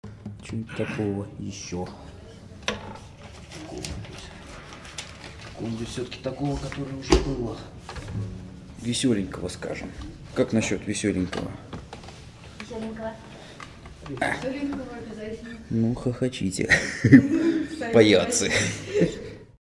Квартирный концерт
Интермедия 1.